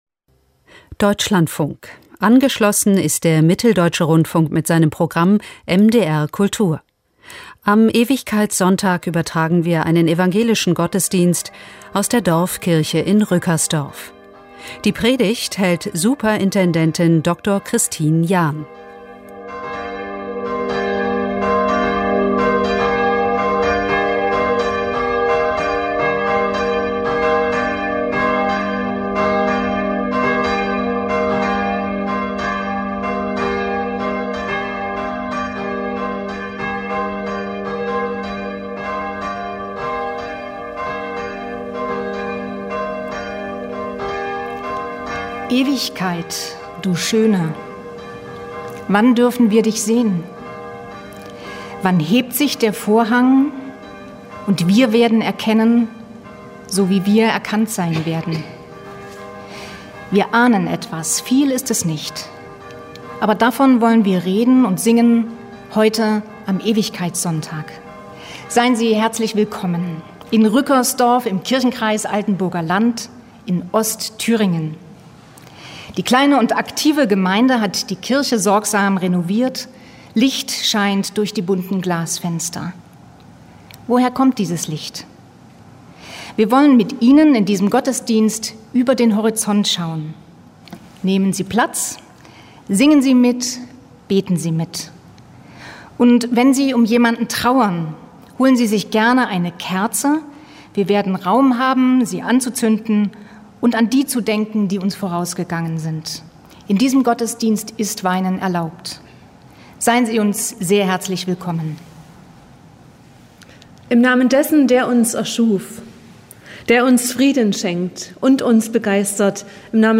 Evang. Gottesdienst 21.11.2021 Rückersdorf